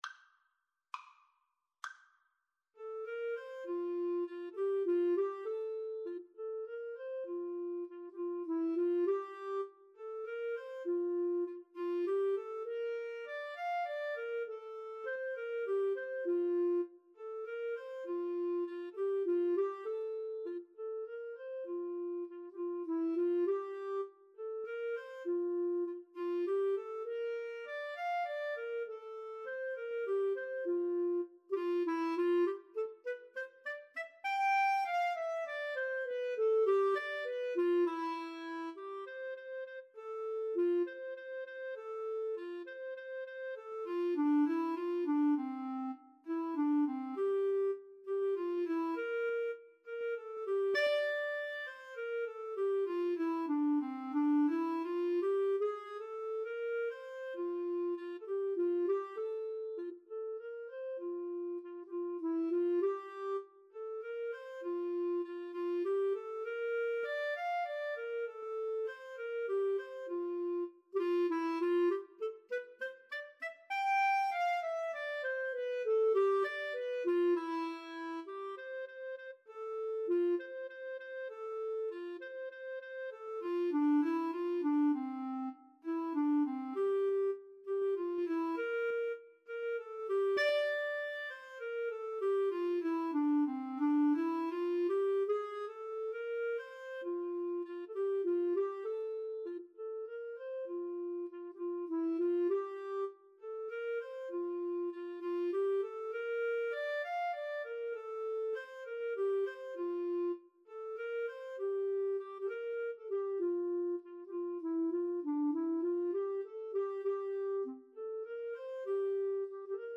Free Sheet music for Clarinet-Cello Duet
6/8 (View more 6/8 Music)
Allegretto
Classical (View more Classical Clarinet-Cello Duet Music)